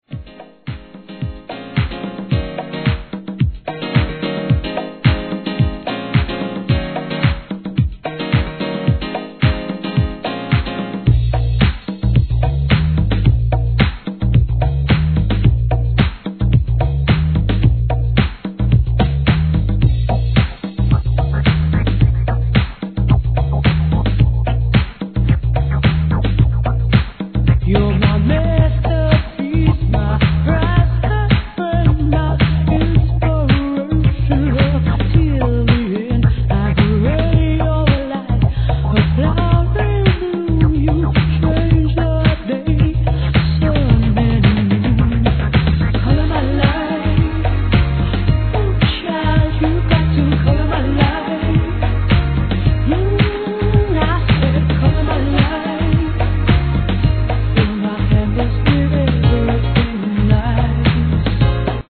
スマートでメロディアスなグラウンドビート!